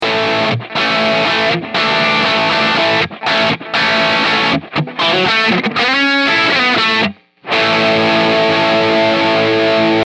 全てフラットなセッティング
ベース５，ミドル５，トレブル５
そのアンプにとって普通の音が出すことが出来ます。
このようにマーシャルらしい一般的な音です。ゲインも５ぐらいになっていますので
歪み過ぎず、クリーン過ぎないドライブ感ですね。